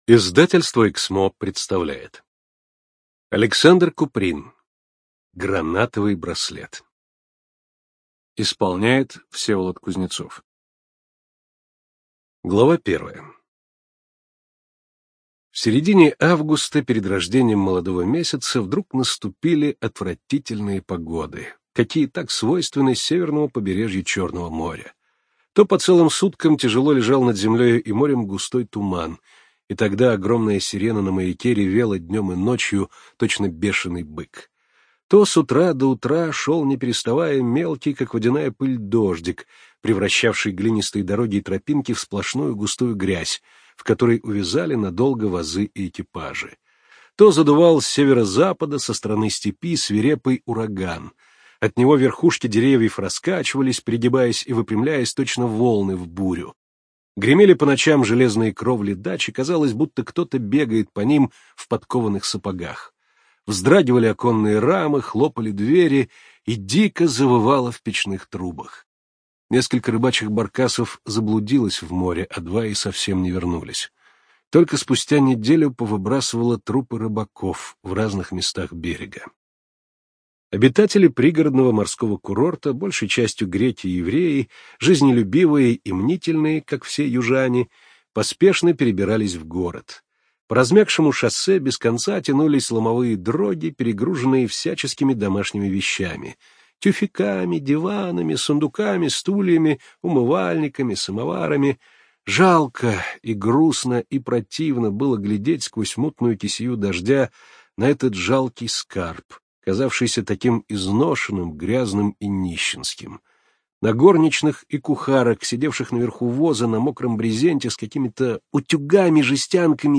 ЖанрКлассическая проза
Студия звукозаписиЭКСМО